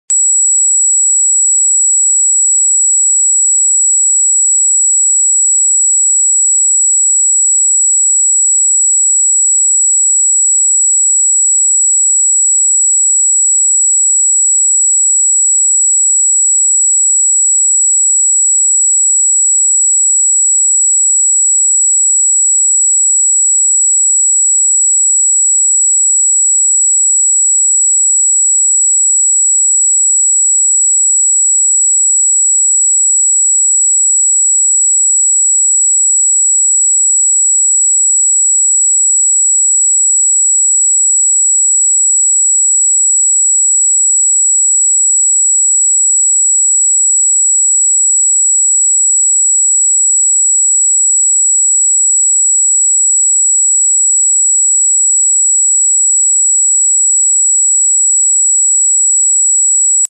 7777Hz Miracle Tone 🌠 Unlock sound effects free download